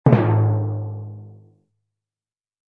Descarga de Sonidos mp3 Gratis: timbal.
descargar sonido mp3 timbal